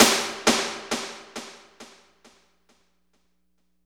51.05 SNR.wav